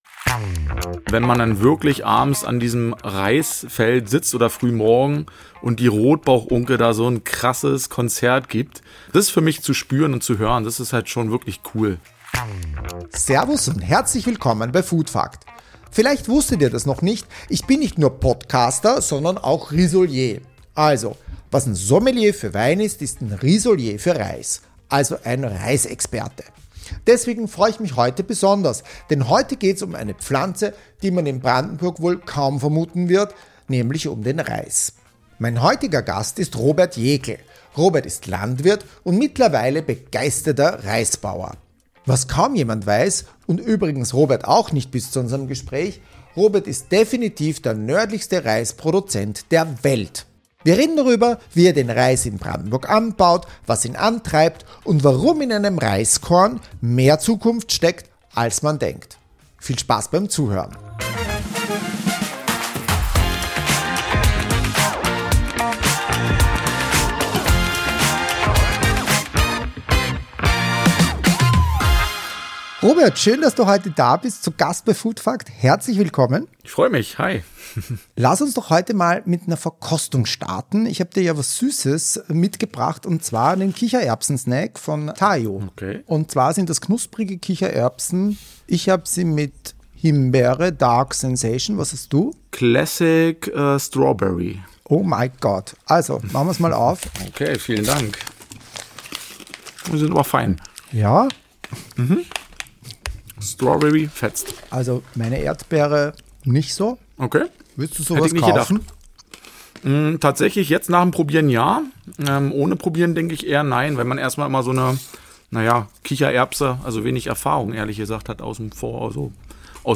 Begleitet wird die Folge von einer leckeren Verkostung und Einblicken in die nachhaltige Landwirtschaft in Deutschland.